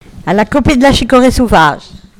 Vendée
locutions vernaculaires